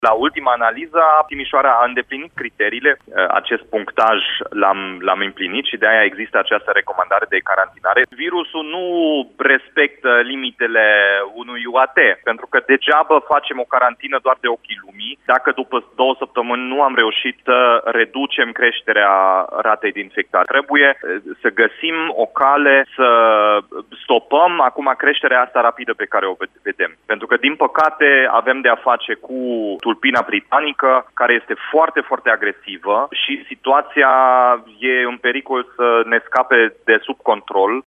Primarul Timișoarei a declarat astăzi, în direct la Radio Timișoara, că ne aflăm, deja, în valul trei al pandemiei, iar tulpina britanică a virusului se răspândește în comunitate.